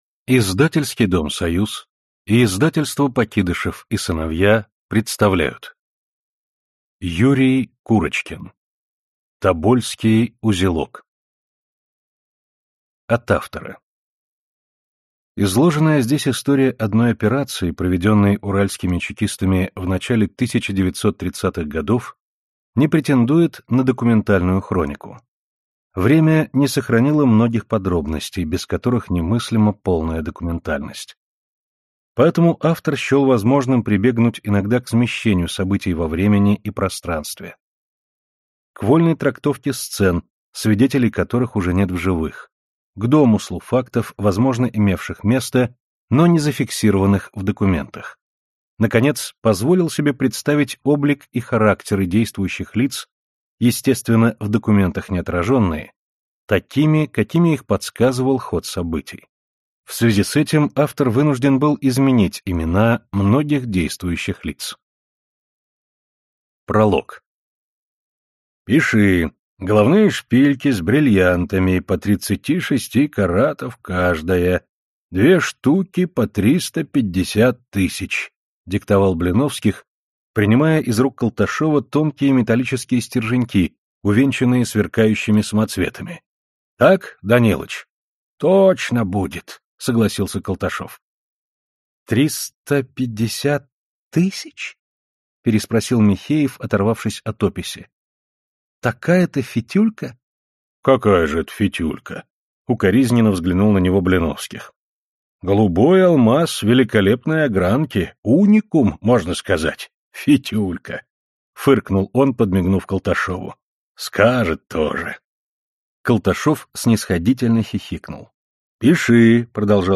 Аудиокнига Тобольский узелок | Библиотека аудиокниг